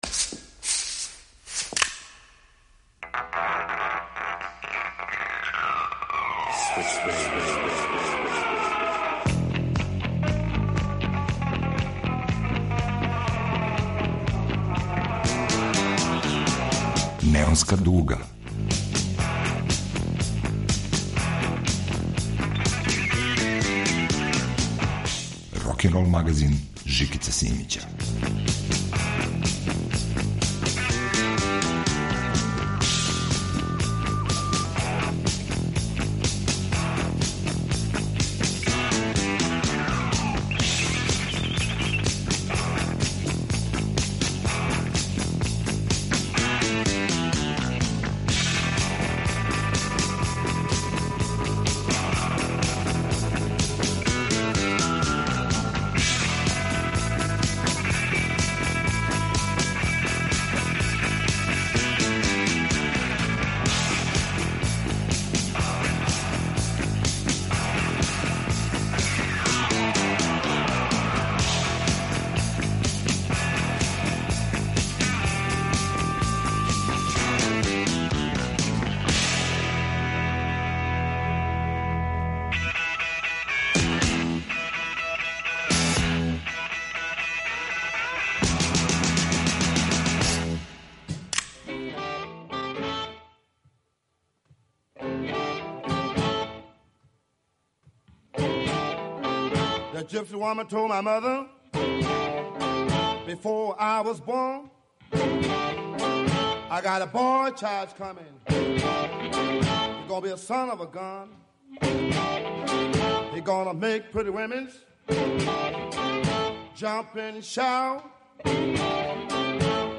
Слушаћемо оригиналне и фабулозне рокерске верзије тих песама, два сата ласцивних ритмова, лаконских стихова и врхунске свирке.